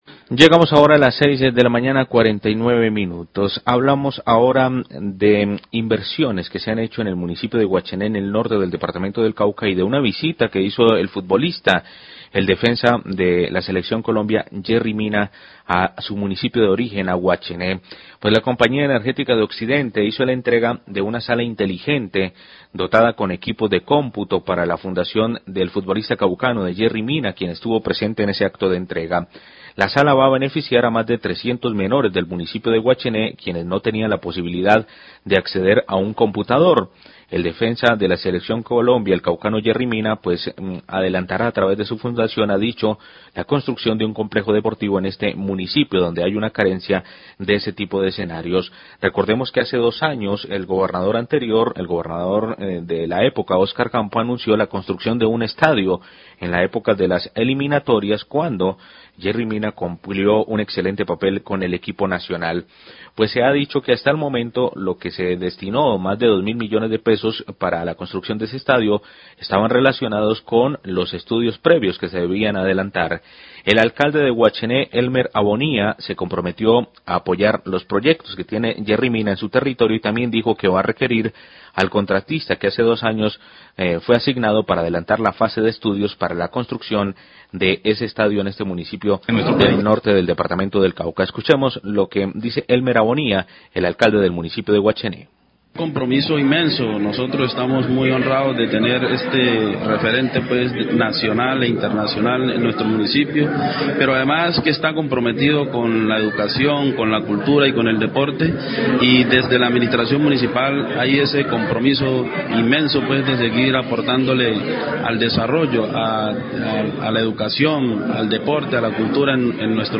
Radio
La Compañía Energética de Occidente, junto a la Fundación de Yerry Mina, entregaron una ula virtual inteligente a la comunidad de Guachené. Declaraciones del alcalde de este municipio, Elmer abonia, quien habla de las necesidades de esta población, especialmente la construcción de un estadio de futbol.